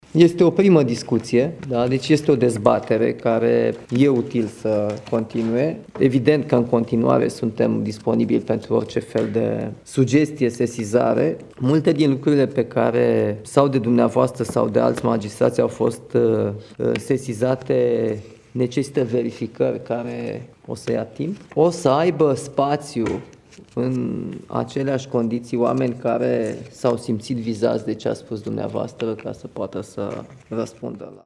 O discuție extrem de utilă, iar dezbaterile vor continua, a declarat șeful statului la finalul întâlnirii.
Întâlnirea de la Palatul Cotroceni cu magistrați și alți actori din sistemul judiciar